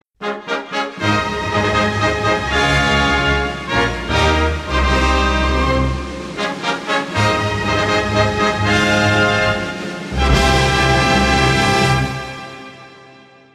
Другие рингтоны по запросу: | Теги: фанфары